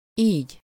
Ääntäminen
IPA: /ˈiːɟ/